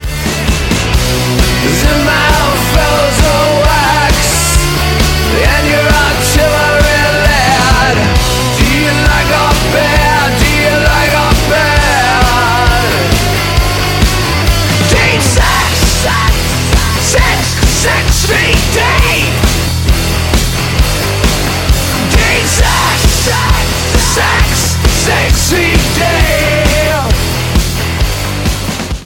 мужской вокал
Industrial rock